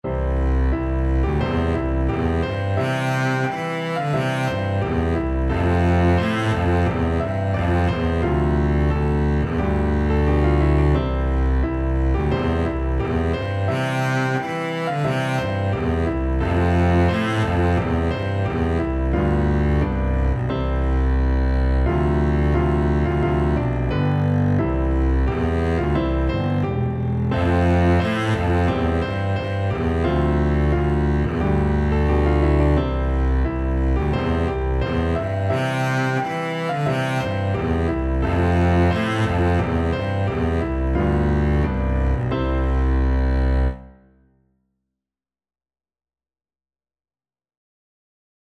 Double Bass
C major (Sounding Pitch) (View more C major Music for Double Bass )
Traditional (View more Traditional Double Bass Music)
Scottish
scotland_the_brave_DB.mp3